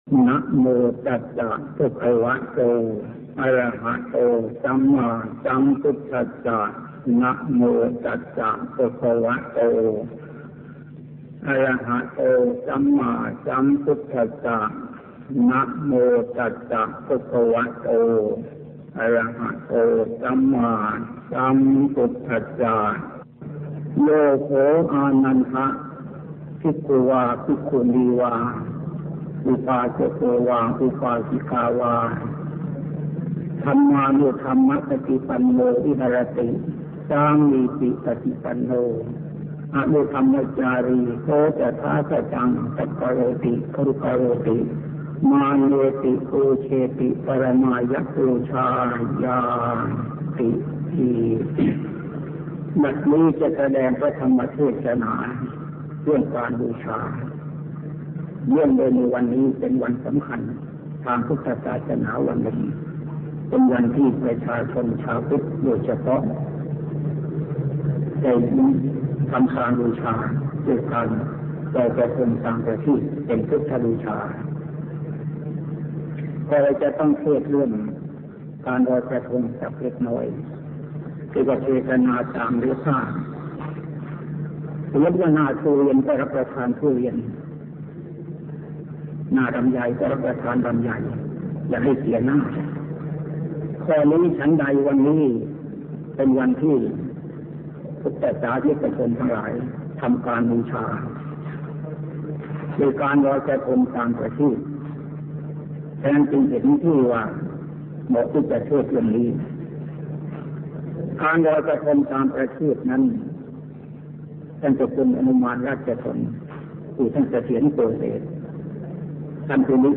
ธรรมเทศนา - พระพรหมบัณฑิต (ประยูร ธมฺมจิตฺโต)